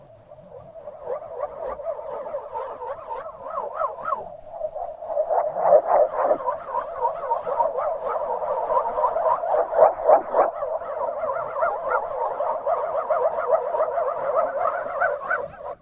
Звуки метеорита
Звук метеоритного дождя в гидролокаторе